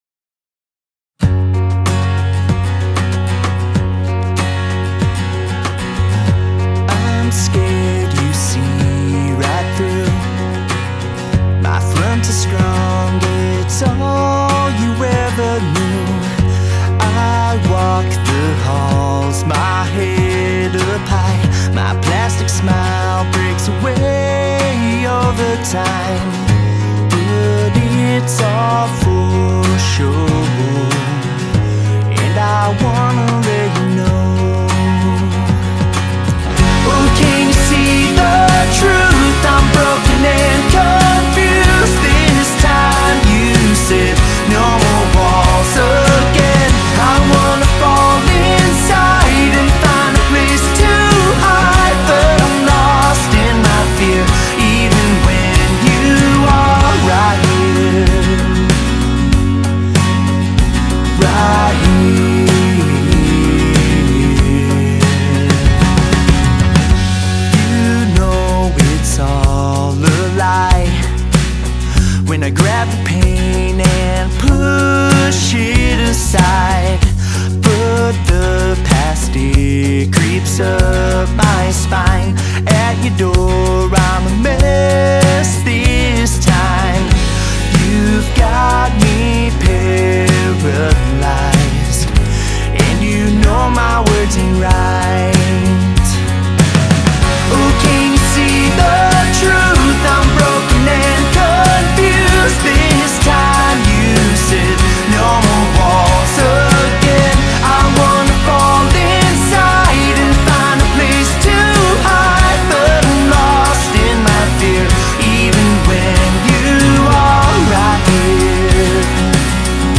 pop rock anthem